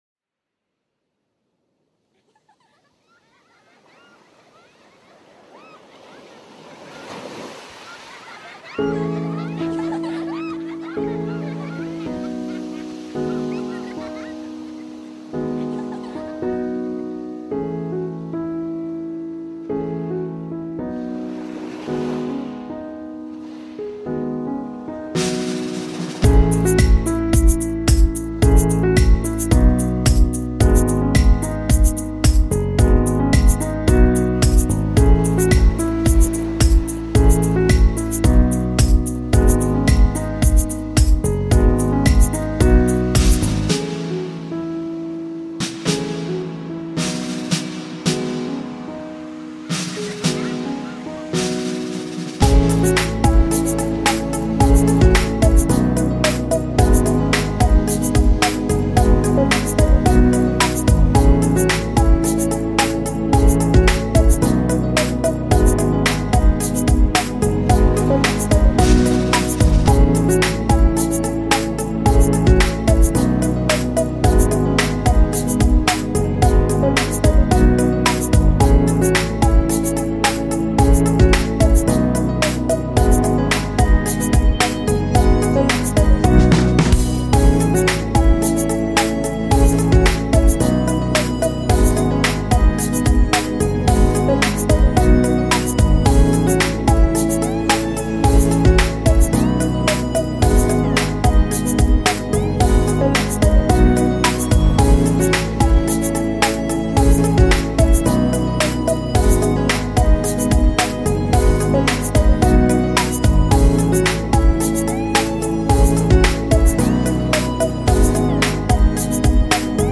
genre:nu disco